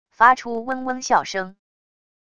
发出嗡嗡啸声wav音频